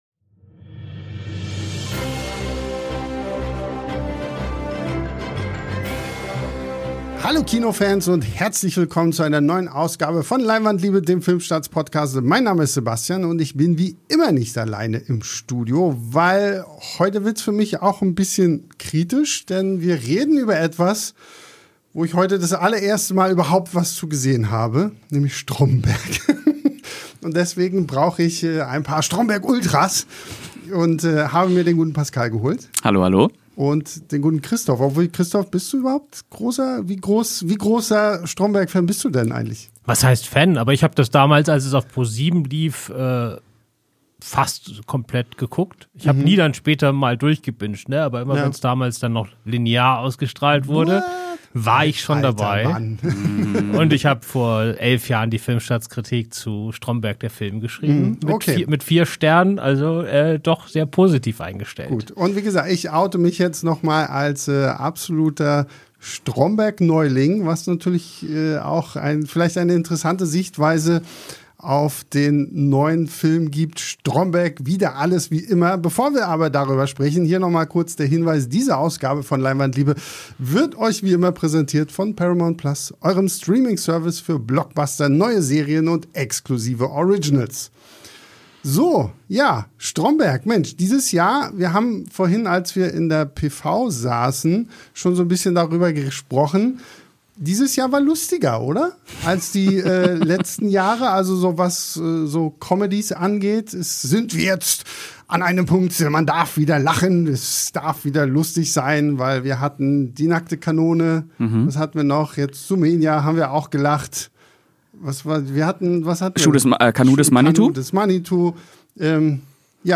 Im Anschluss an den Podcast hört ihr noch ein Interview mit "Stromberg"-Schöpfer und Autor Ralf Husmann.